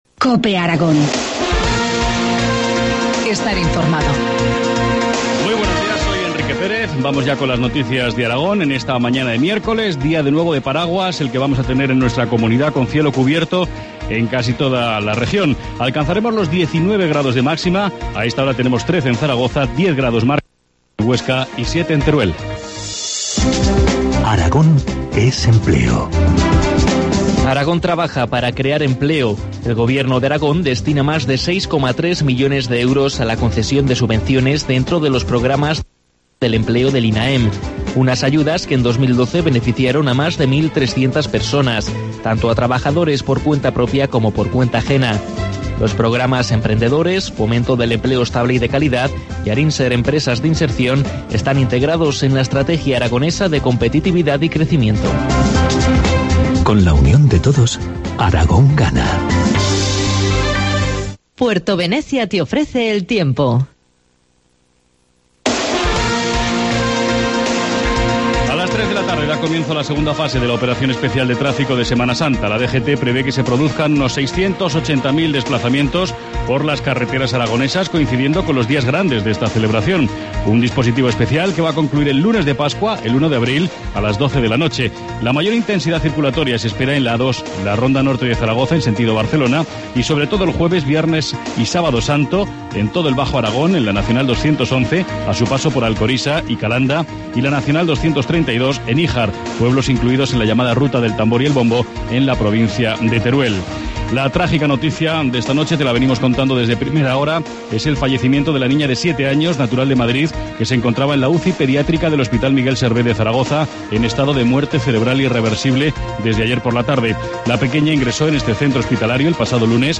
Informativo matinal, miércoles 27 de marzo, 8.25 horas